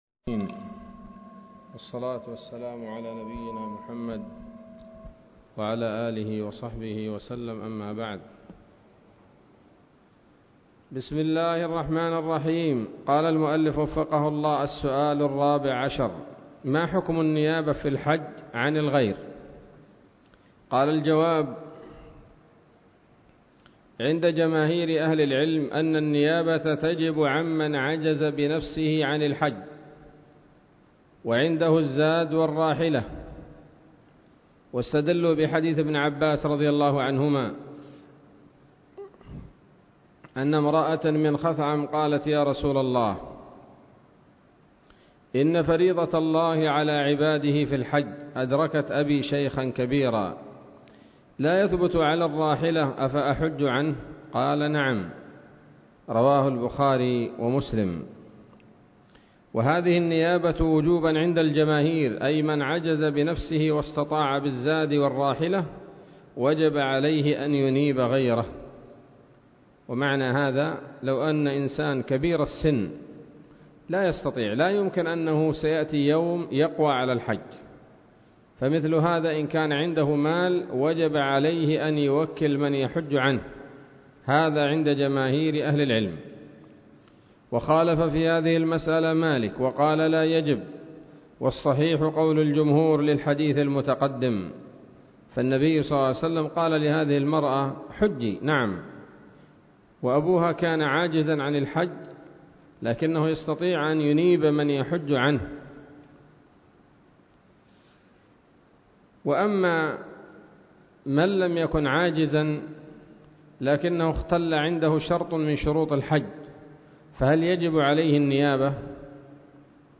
الدرس الثاني والعشرون من شرح القول الأنيق في حج بيت الله العتيق